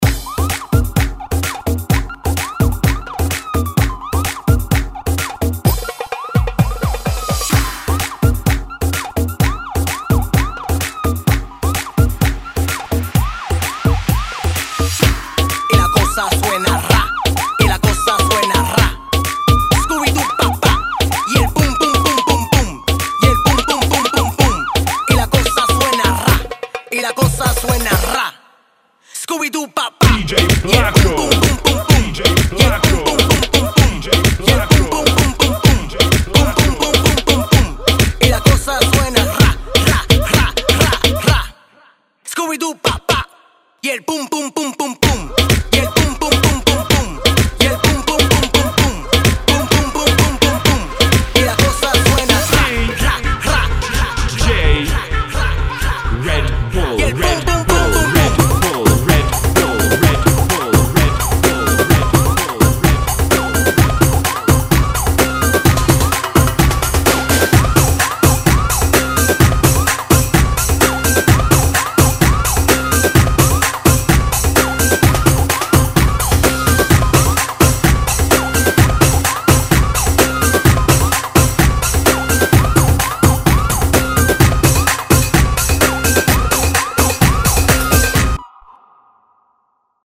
128 bpm